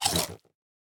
1.21.5 / assets / minecraft / sounds / mob / strider / eat1.ogg
eat1.ogg